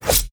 sfx_attack_01.wav